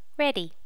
Initial check in of the sounds for the notify plugin.